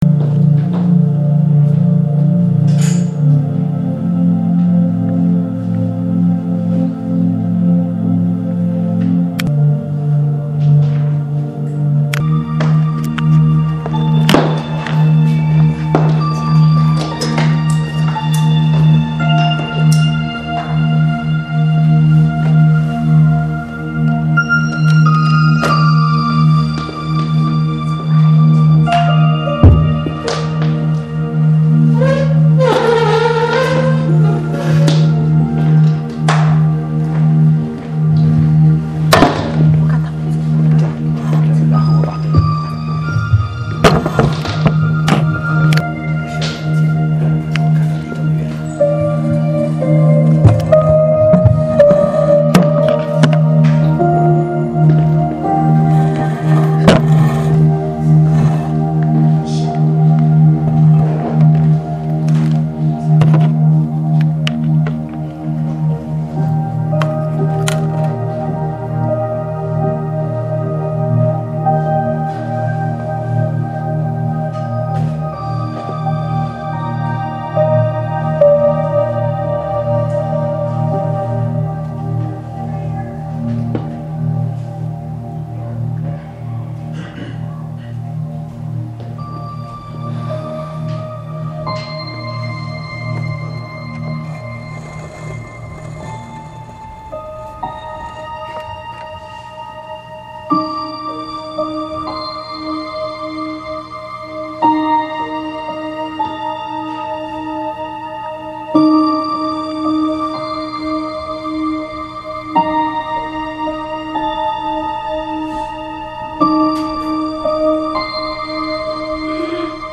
正在播放：--主日恩膏聚会（2015-03-15）